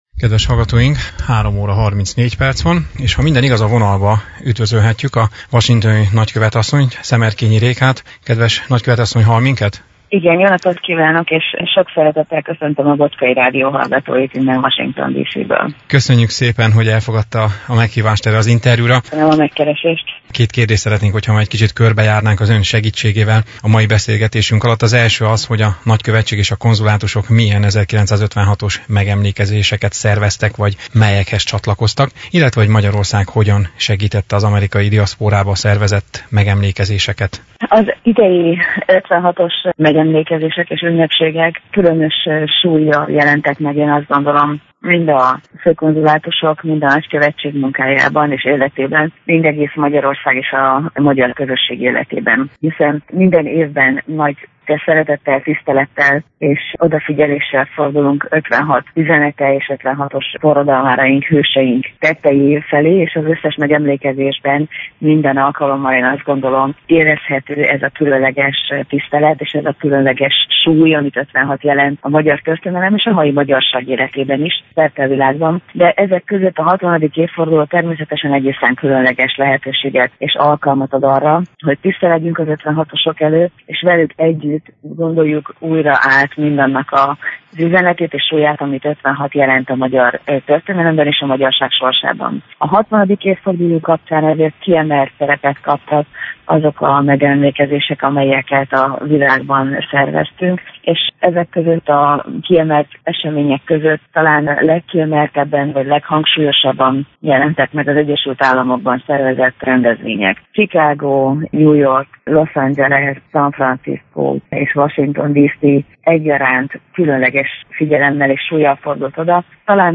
2016-ban az 1956-os események 60. évfordulójára emlékezik minden magyar a földkerekségen. Ennek kapcsán a washingtoni magyar nagykövet asszonnyal, dr. Szemerkényi Rékával egy telefonos interjú készült, amelyben az a kérdés tevődött fel, hogy a Nagykövetség és a konzulátusok Észak-Amerikában milyen 1956-os megemlékezéseket szerveztek e nemzeti ünnepnap apropóján.
SzemerkényiRéka-telefonInterju56.mp3